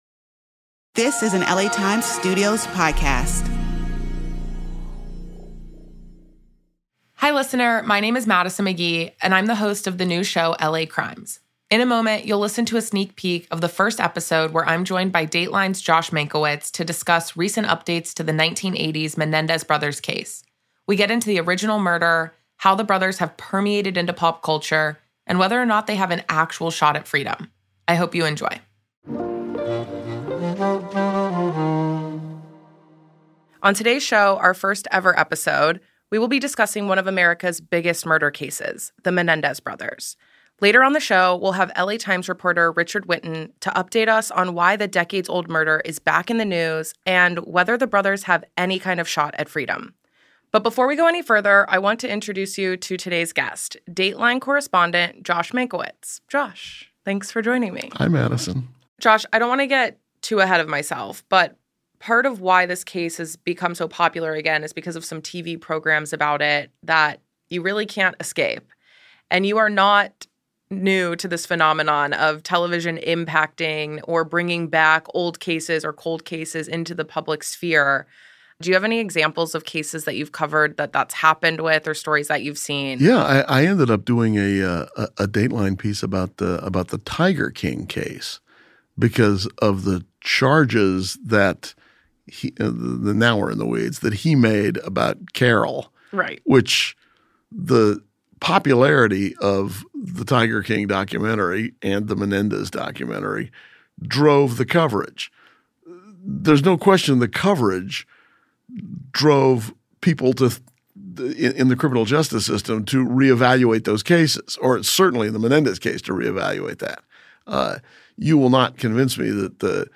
legendary Dateline host Josh Mankiewicz